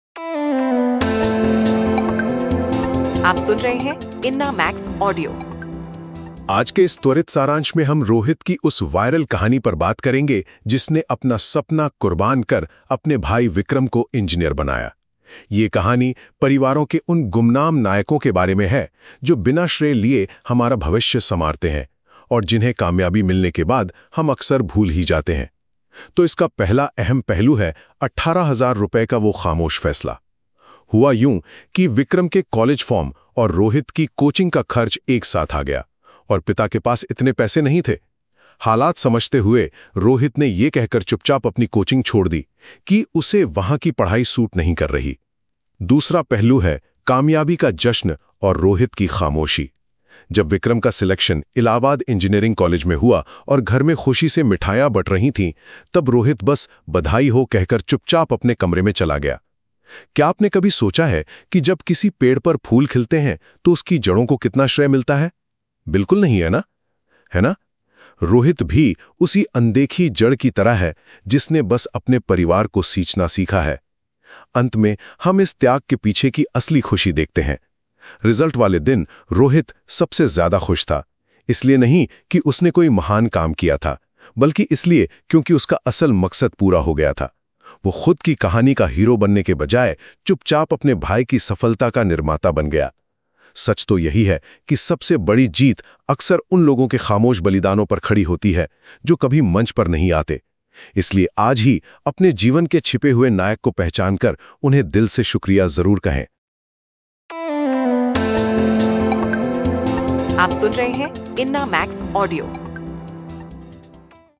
bade-bhai-ki-chuppi-innamax-voice-story.mp3